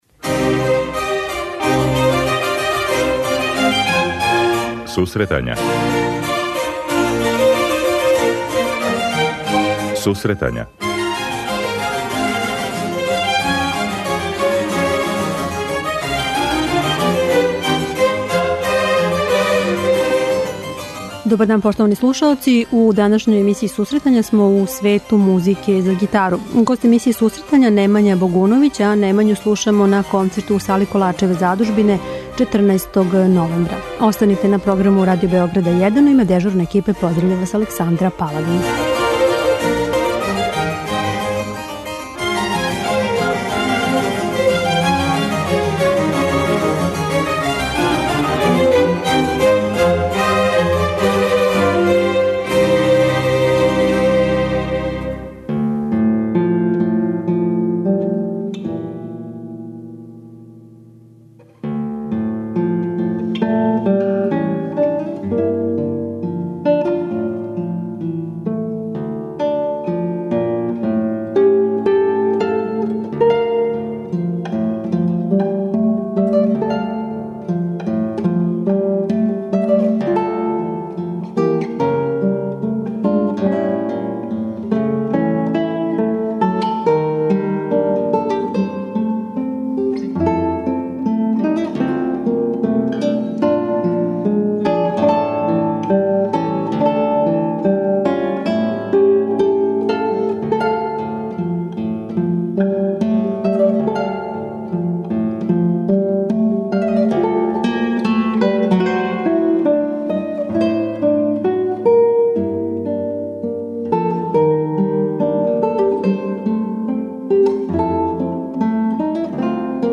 Сусретања су данас у звуку музике за гитару.